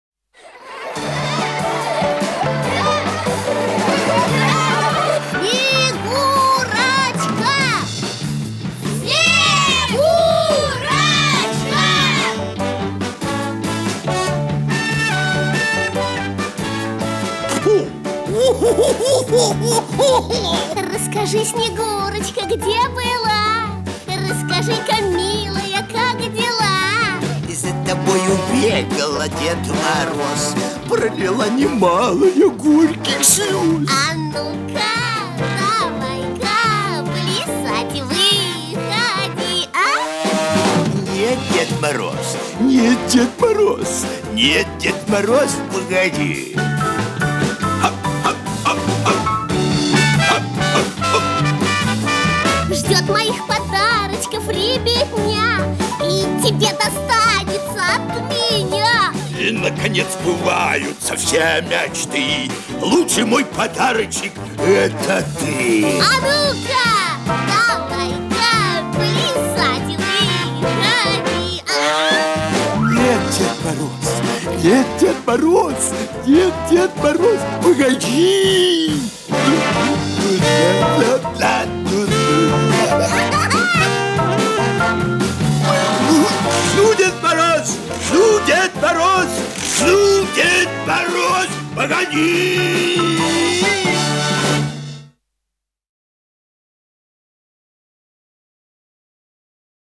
музыка детская